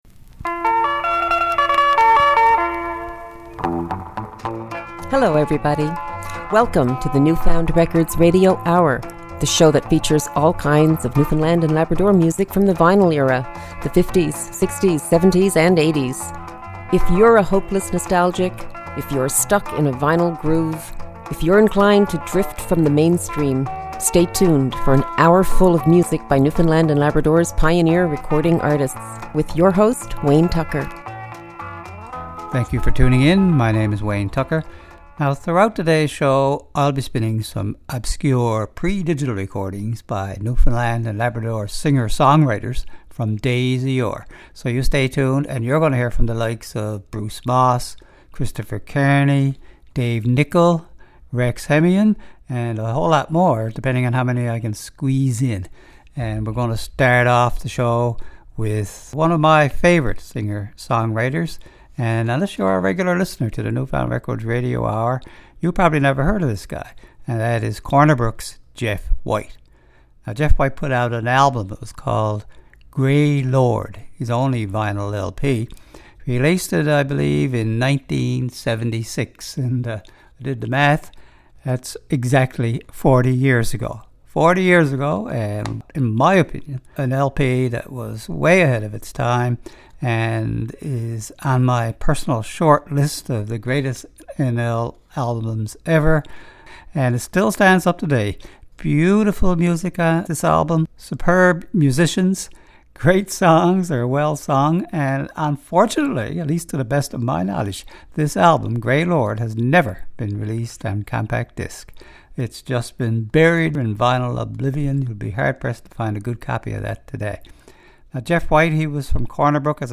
Recorded at CHMR studios, MUN, St. John's, NL.